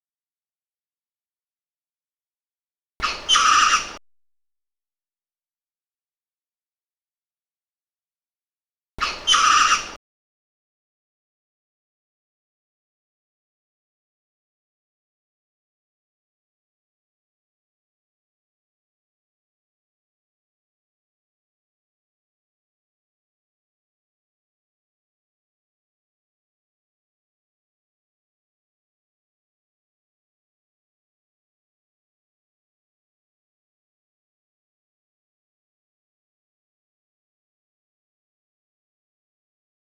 Audio file of one loud call of Eulemur albifrons. (WAV 3746 kb)